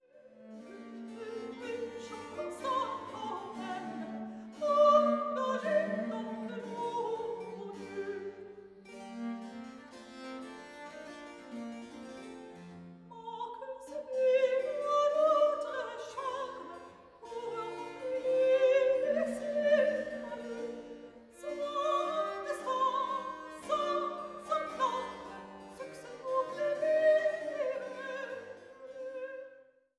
Sopran
Traversflöte
Viola da gamba
Cembalo